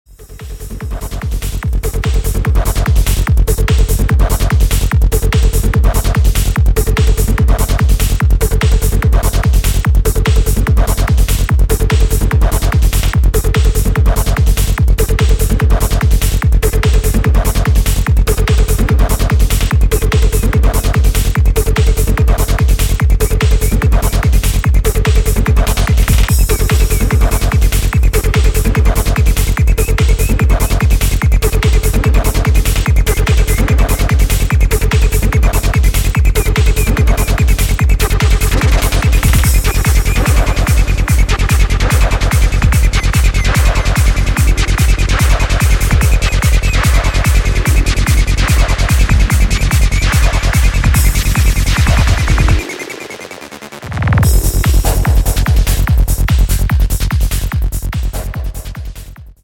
France Style: Psy-Trance 1